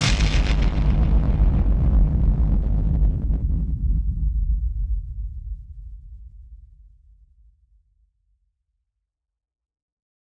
BF_DrumBombC-05.wav